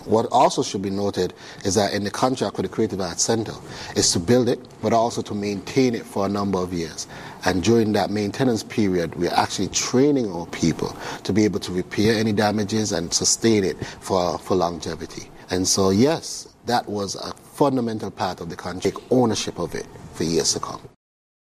Minister Duggins also shared this about the contract for the project: